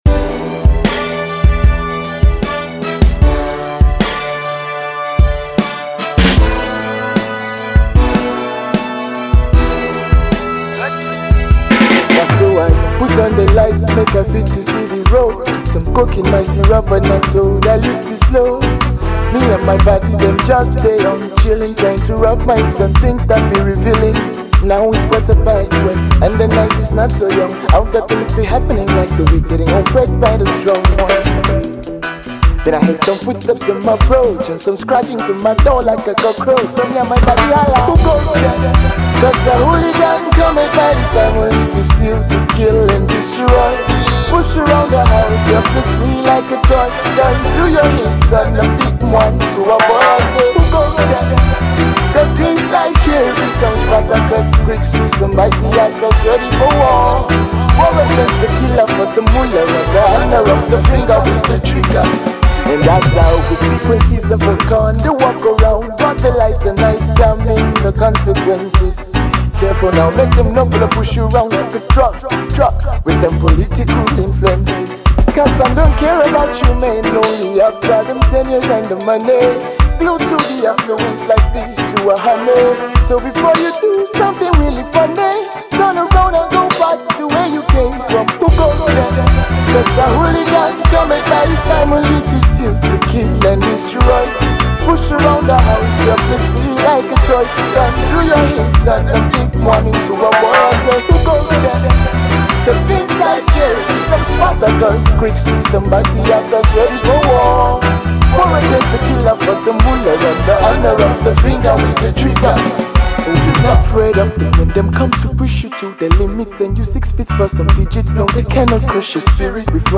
indigenous Hip-Hop